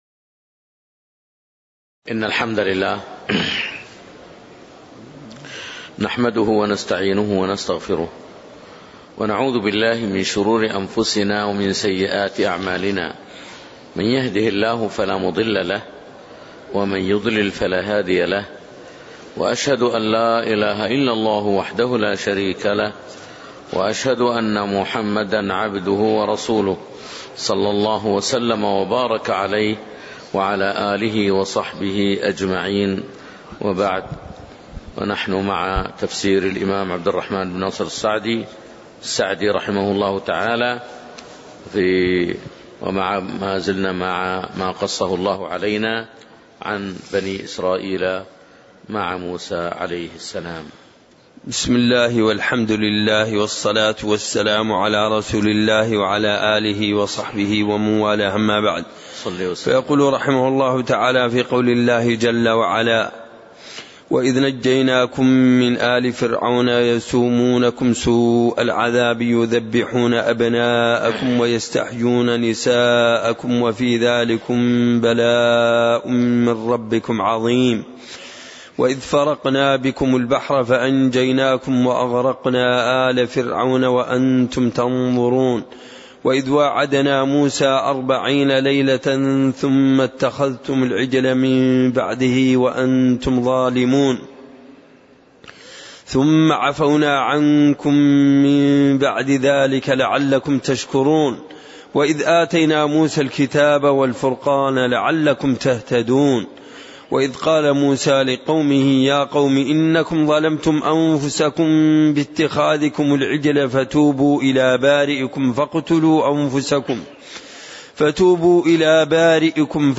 تاريخ النشر ٢١ محرم ١٤٣٨ هـ المكان: المسجد النبوي الشيخ